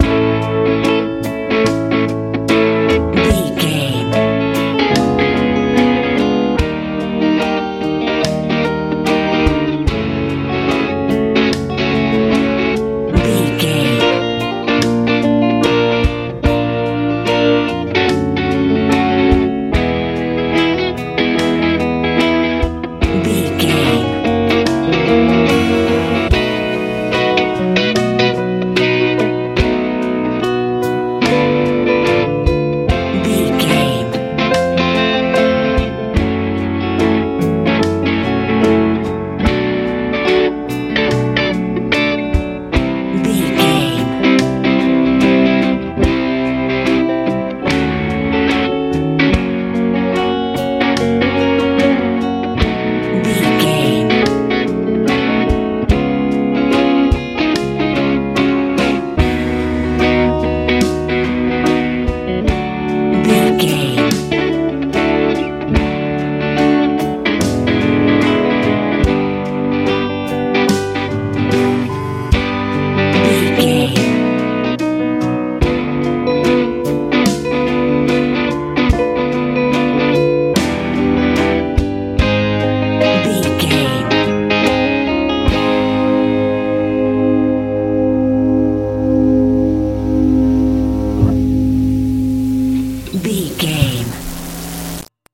lite pop feel
Ionian/Major
bass guitar
drums
piano
electric guitar
sweet
soft
peaceful
melancholy
mellow